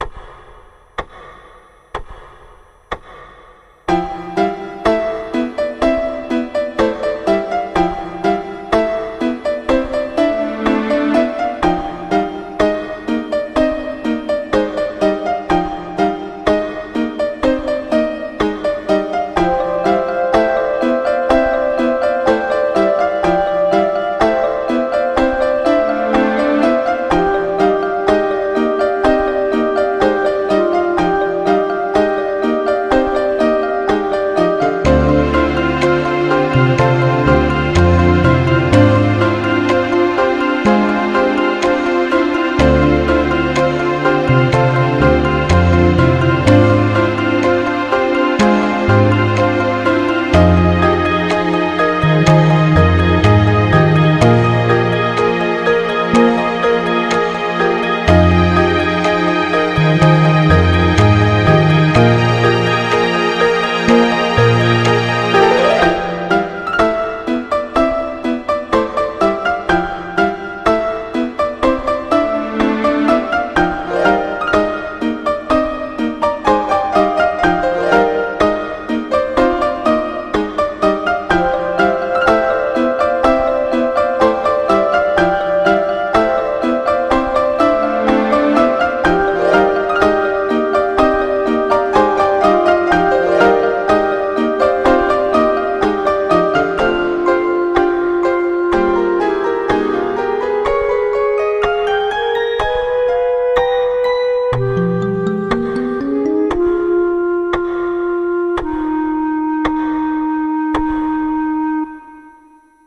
BPM58-120
Audio QualityPerfect (Low Quality)